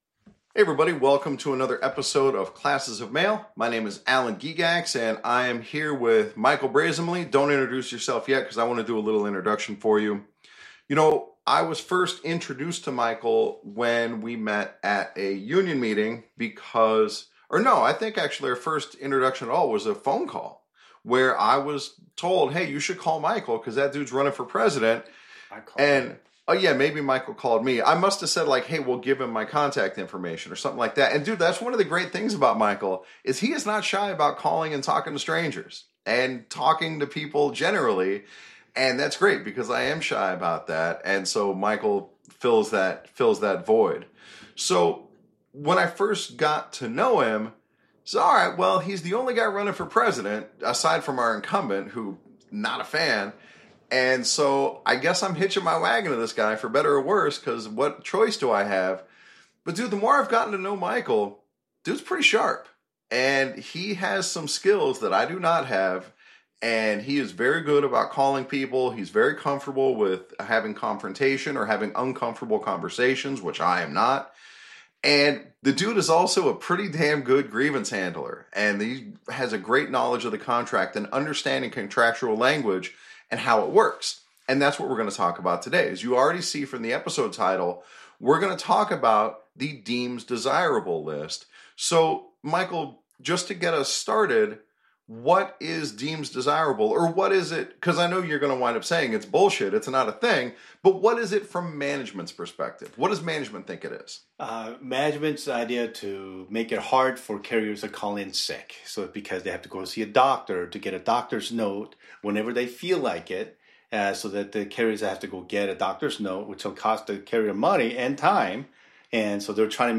FYI, this episode was recorded in my back yard, before I got my new audio set up. Please bear with the sound quality.